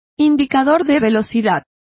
Complete with pronunciation of the translation of speedometer.